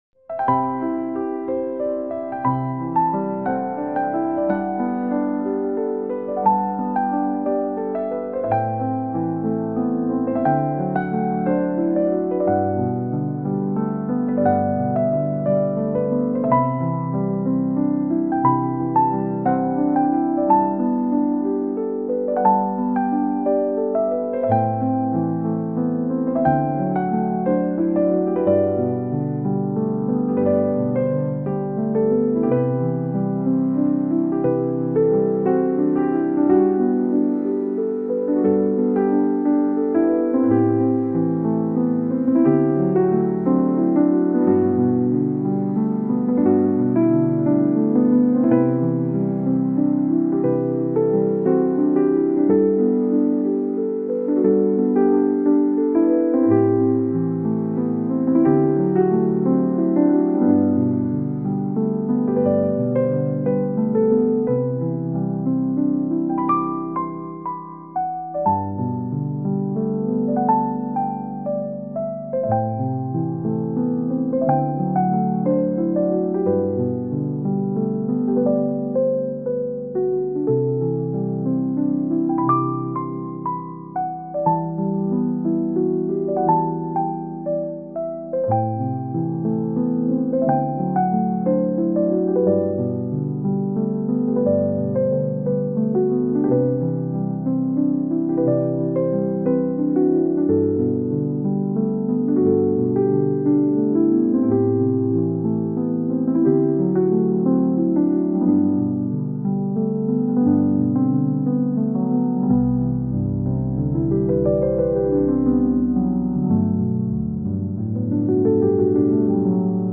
Piano in a Zen Garden of Stillness